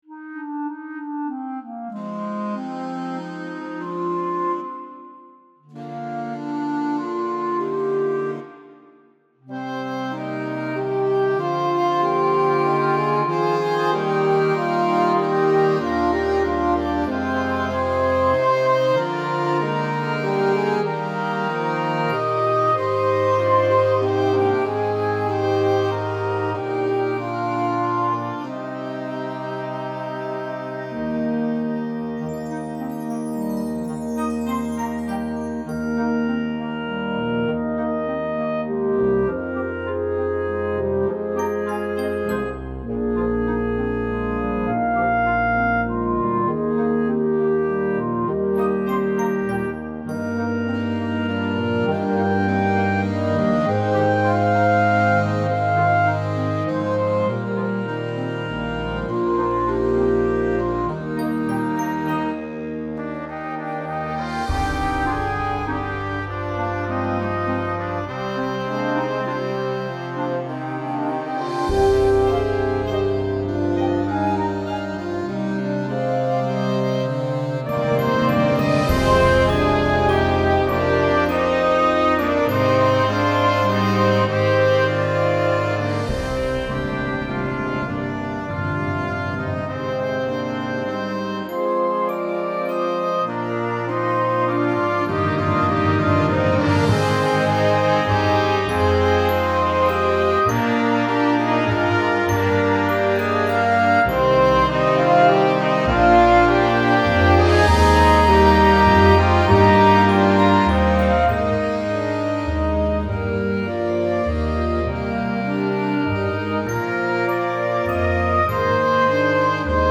Concert / Wind Band – Grade 2.5